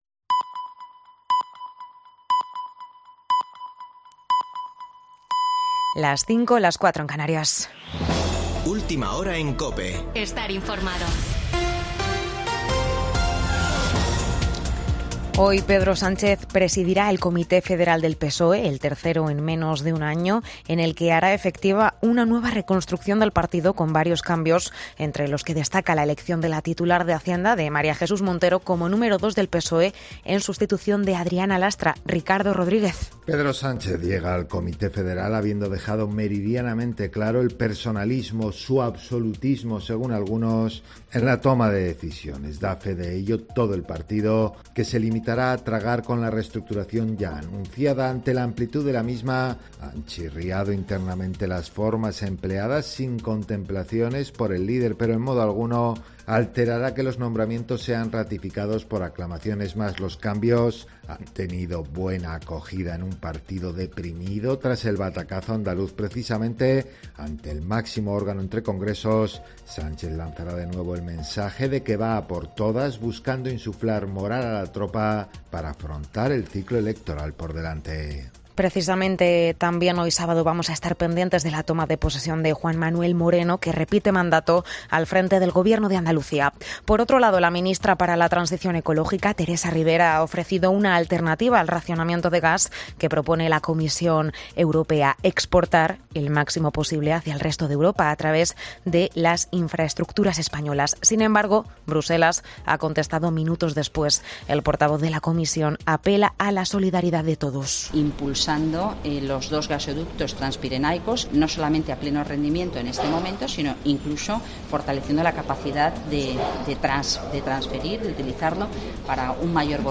Boletín de noticias de COPE del 23 de julio de 2022 a las 05.00 horas